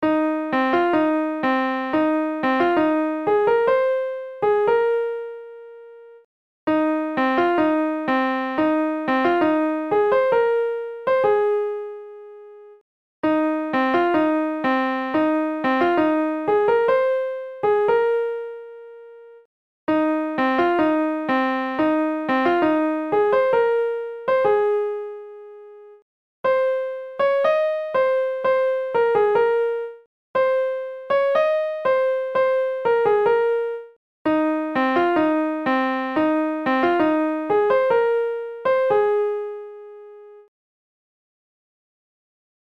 『どこかで春が』　百田宗治作詞　草川信作曲　1923年（大正12）
たどたどしい一本指の演奏ですが、ご容赦下さい。
MIDIのソフトで初めて作成してみました。